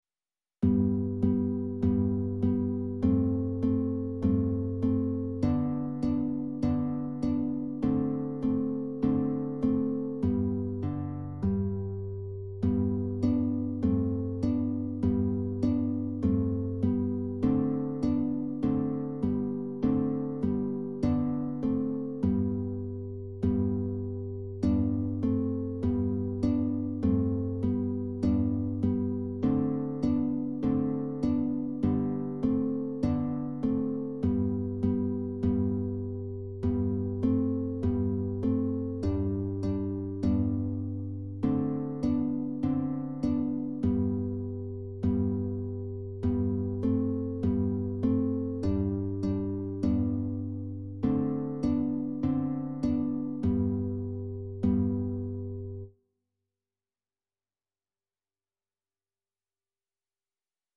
Beginner Classical Guitar - Lesson 2: Notes on the E string
The one contains both parts of the music and the other contains the accompaniment.
In Exercise 5 you will be playing Whole Notes; in Exercise 6 half notes and in Exercise 7 quarter notes.